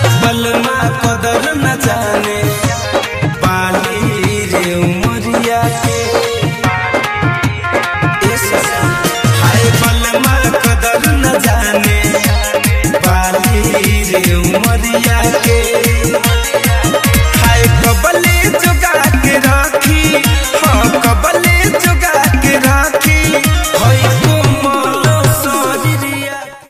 Ringtone File